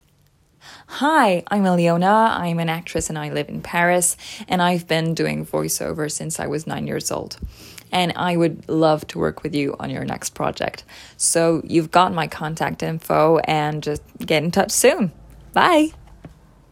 English presentation - AMERICAN ACCENT